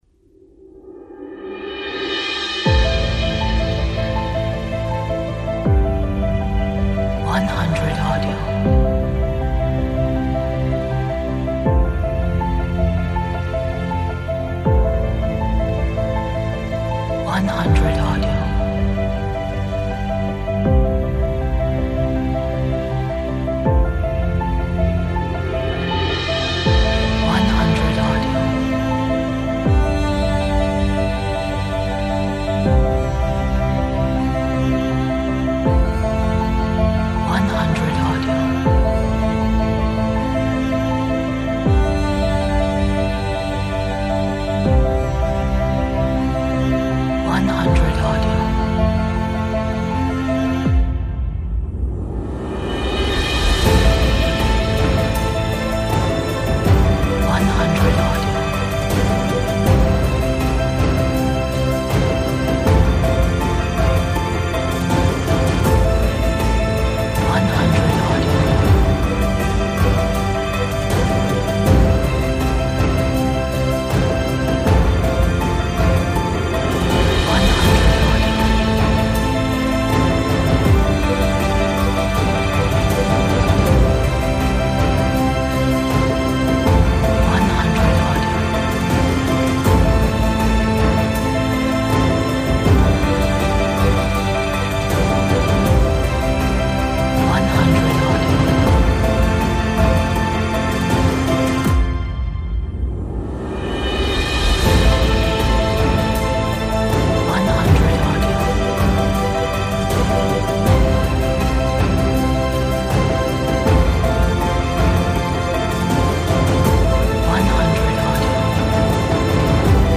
Epic cinematic background track for different projects!